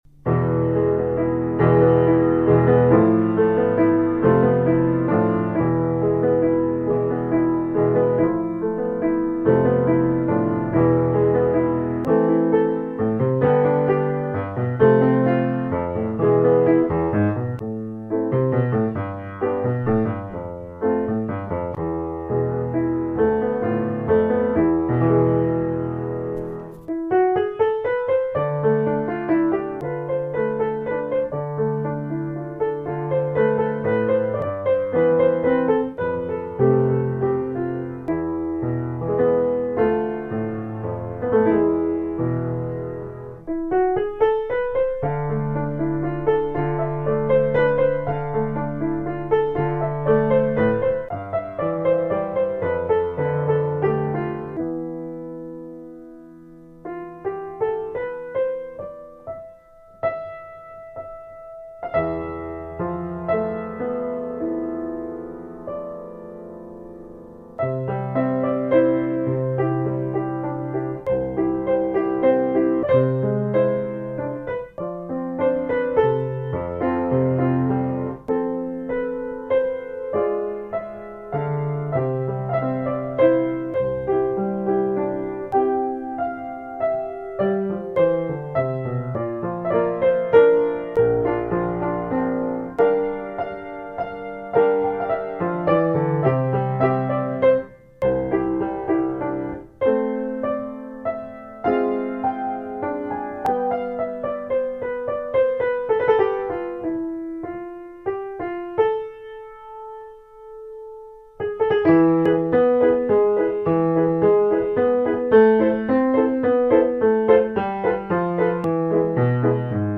traditional Spanish dance
It has a vibrant, Flamenco style theme and rhythm.